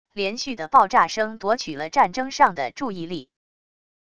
连续的爆炸声夺取了战争上的注意力wav音频